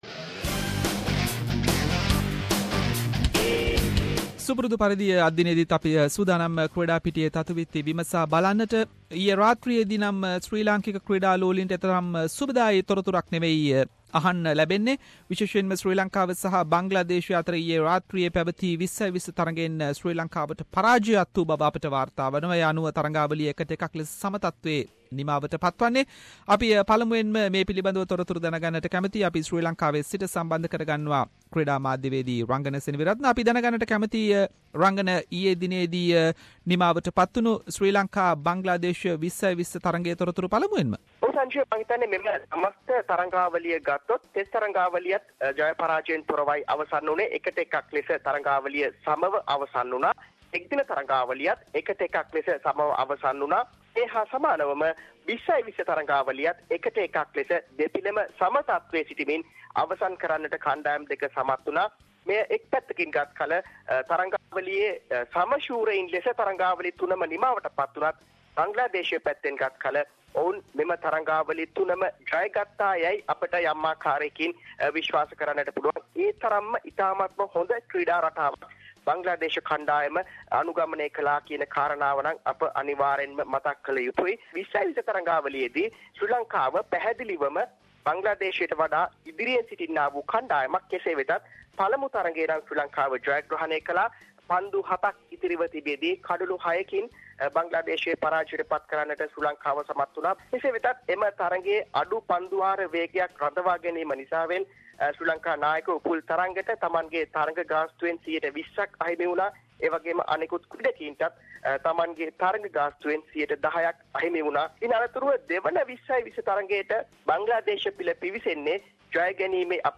sports wrap